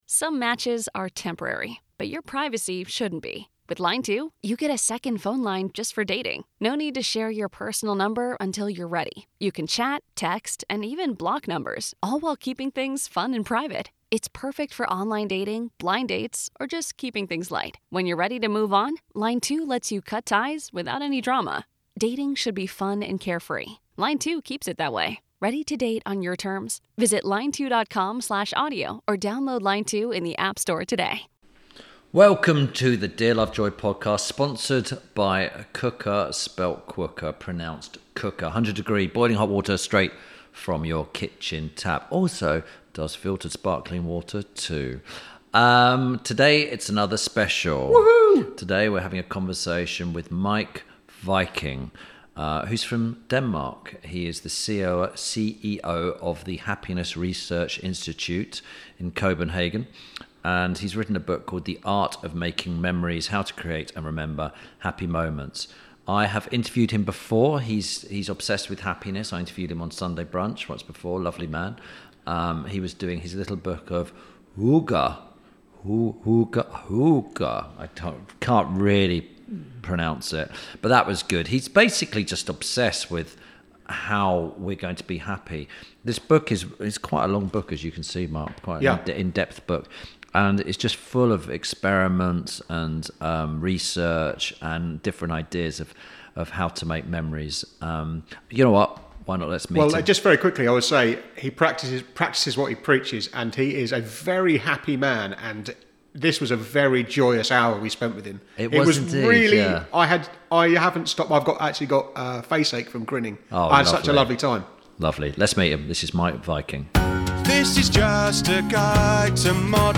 Ep. 145 – MEIK WIKING/HAPPINESS- A Conversation With… – INTERVIEW SPECIAL
This week Tim Lovejoy talks to author and founder/CEO of The Happiness Research Institute in Copenhagen, Meik Wiking. Whilst discussing his book The Art of Making Memories, they talk about what happiness is, how it is measured and what, as humans, makes us happy.